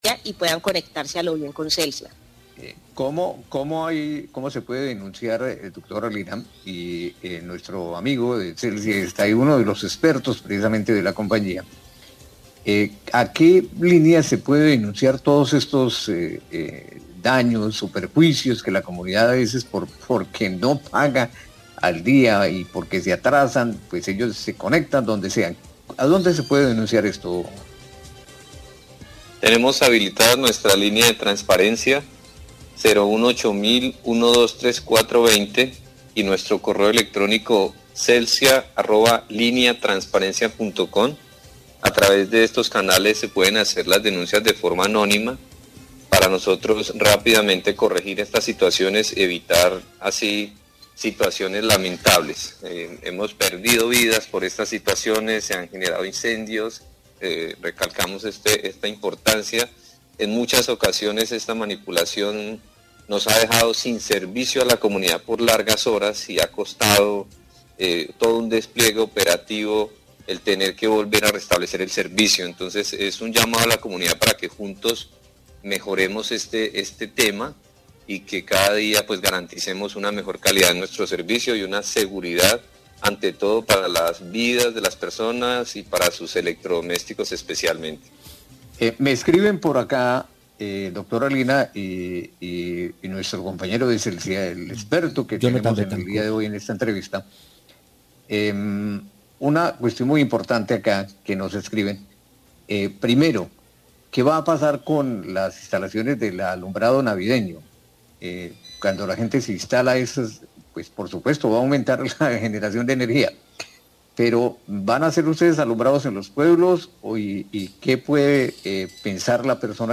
Radio
Periodista le pregunta si Celsia realizará alumbrado navideño en los pueblos del Tolima.